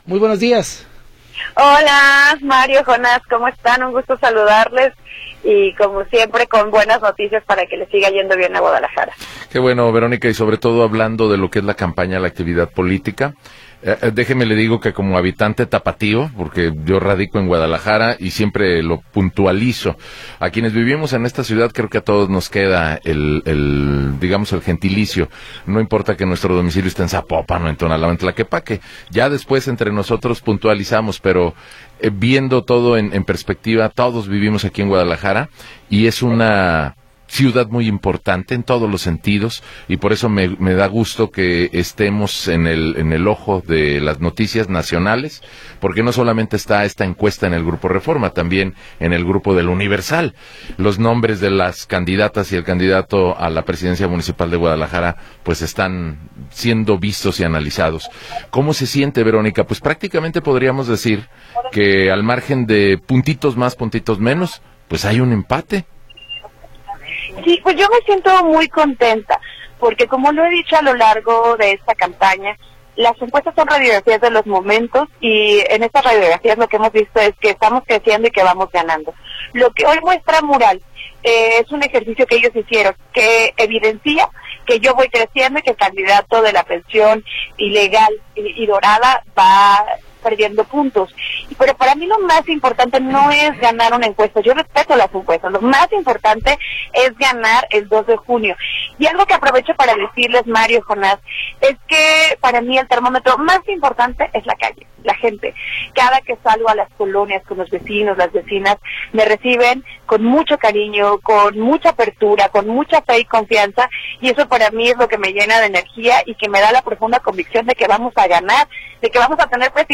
Entrevista con Verónica Delgadillo